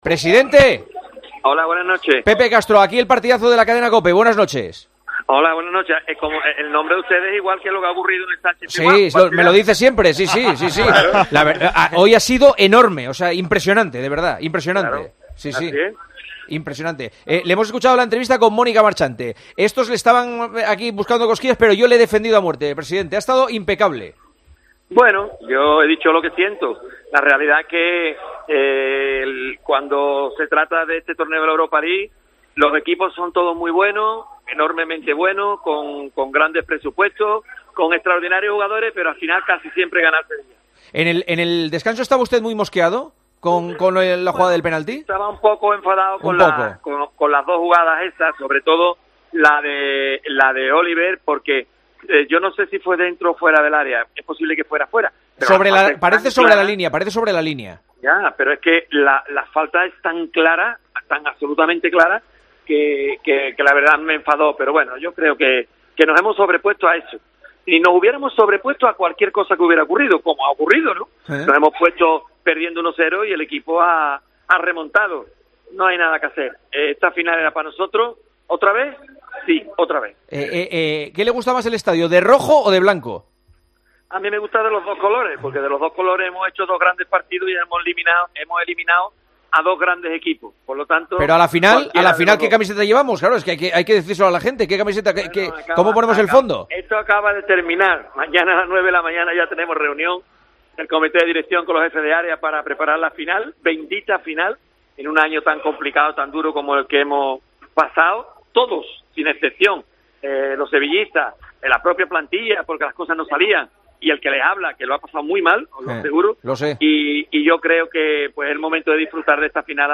Escucha la entrevista de Juanma Castaño a Pepe Castro en El Partidazo de COPE tras el pase a la final